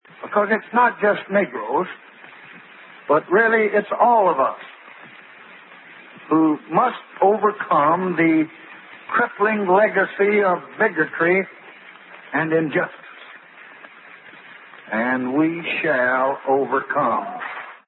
Вот коротенький фрагмент из этого выступления президента Джонсона, положившего начало своеобразному «приручению» песни-«мы»:
(современное американское ухо было бы, несомненно, возмущено тем, что сам президент, ничуть не стесняясь, называет вещи своими именами и постоянно употребляет слово «Negroes» — «негры»; но сорок лет назад, очевидно, это слово казалось ещё вполне приличным).